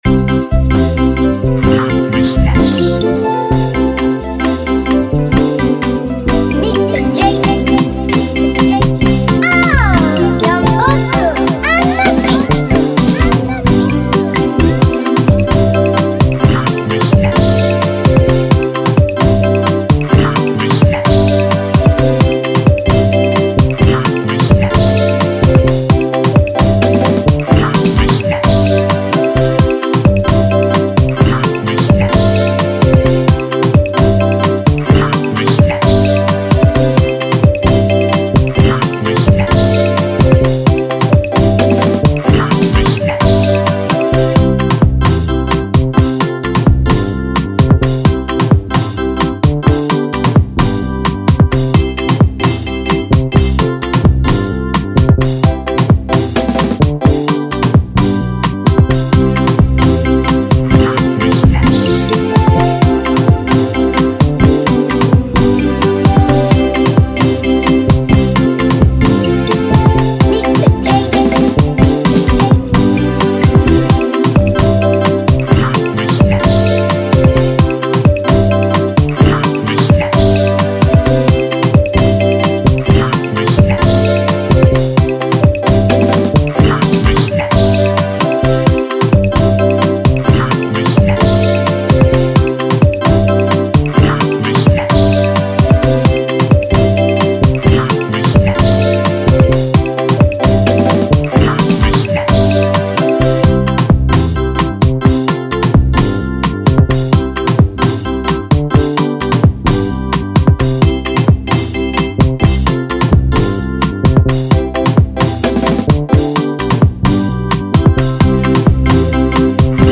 instrumental
Instrumentals